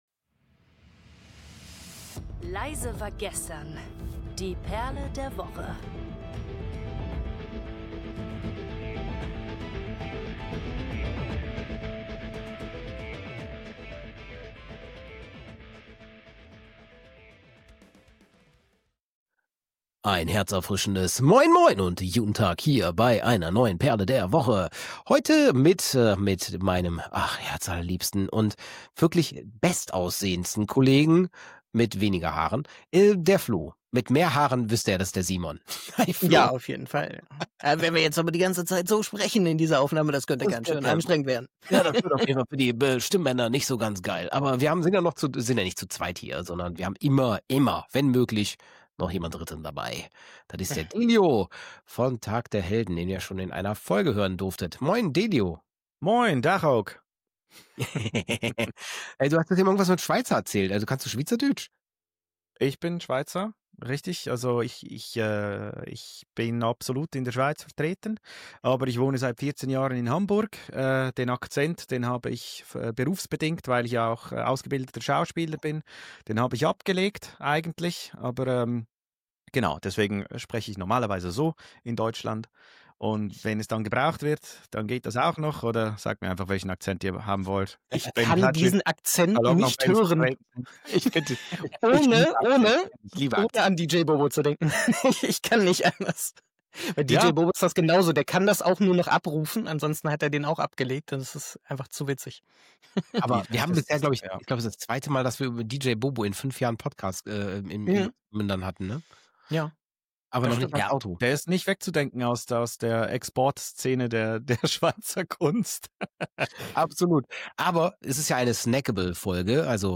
Mit viel Humor, nerdigen Details und spontanen Song-Einwürfen teilen die Hosts ihre persönlichen Highlights der Trommelkunst.
05:37 – Musikhighlight: Dream Theater – 600 🎵